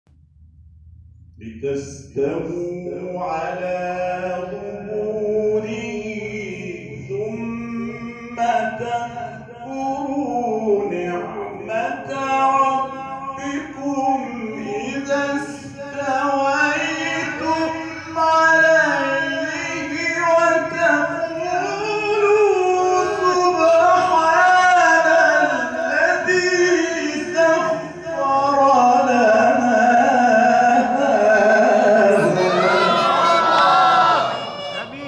گفتنی است؛ جلسه تلاوت این سوره مبارکه در مسجد منصوریه اردبیل برگزار شد.
قاری اردبیلی ، سوره زخرف